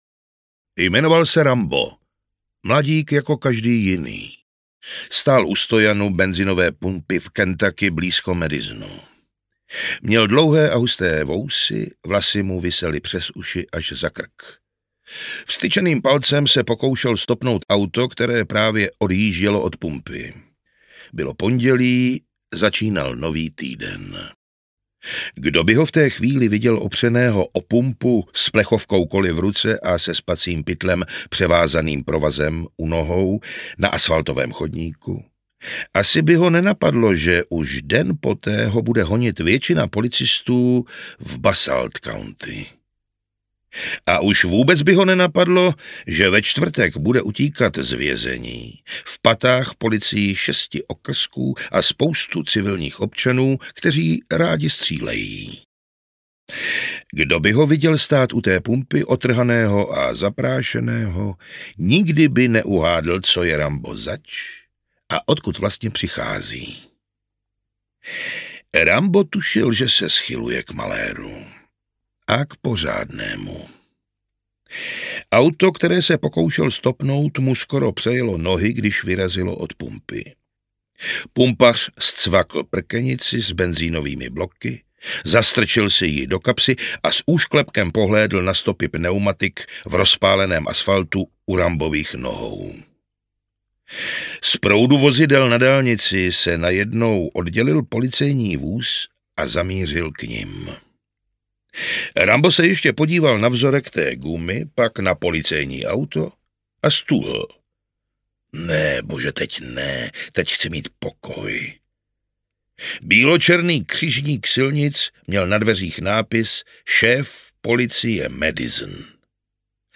Rambo – První krev audiokniha
Ukázka z knihy